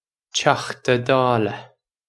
Pronunciation for how to say
Choktuh Dawluh
This is an approximate phonetic pronunciation of the phrase.